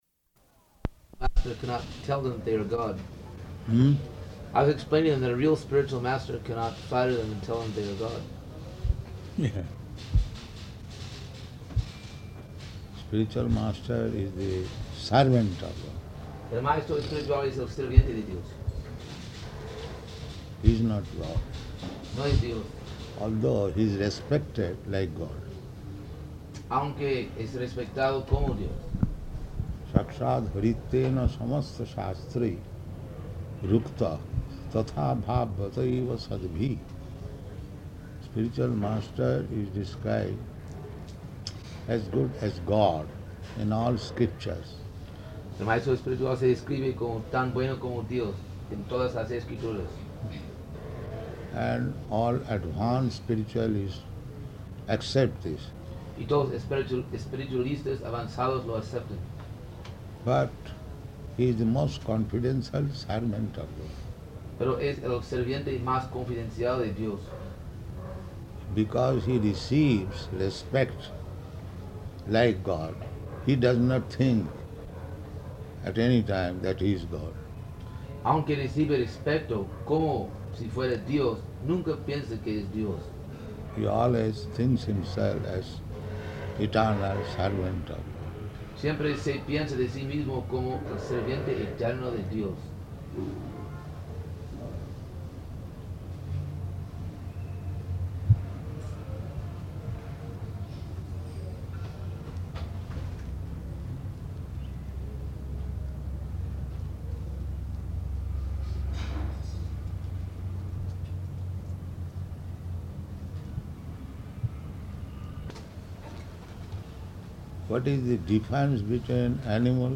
Room Conversation with Psychiatrists
Type: Conversation
Location: Caracas